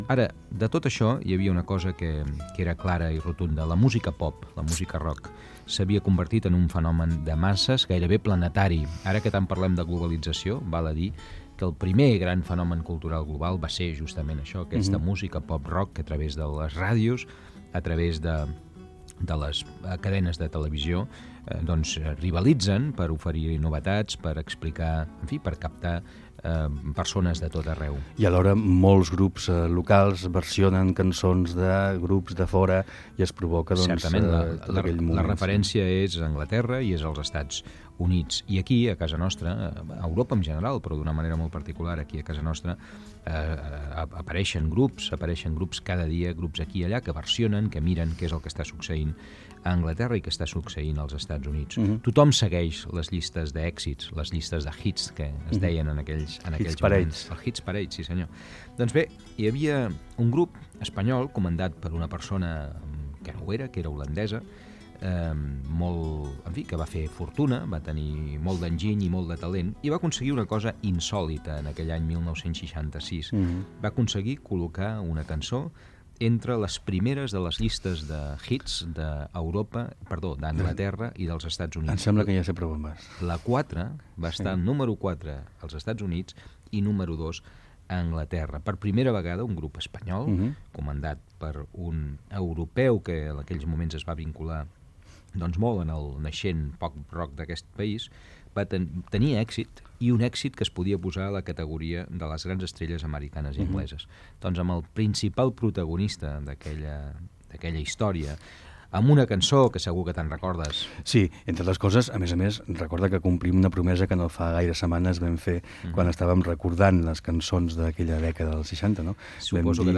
Fragment d'una entrevista al cantant Mike Kennedy, en connexió amb Ràdio Salt. Recorden l'èxit de la cançó "Black is black", editada l'any 1966.
Entreteniment